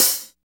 Index of /90_sSampleCDs/Roland - Rhythm Section/KIT_Drum Kits 2/KIT_Dry Kit
HAT REAL H07.wav